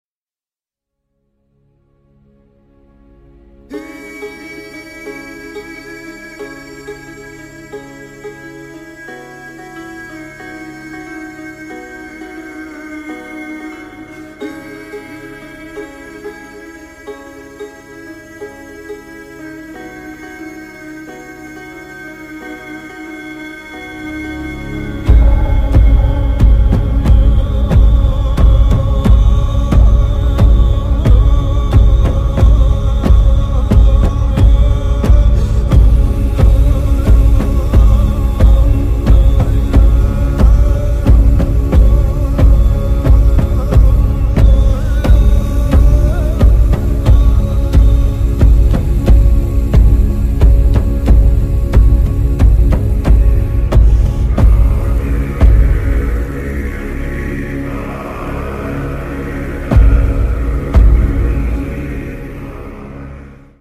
• Качество: 128, Stereo
атмосферные
инструментальные
dark ambient
этнические
neofolk
dark folk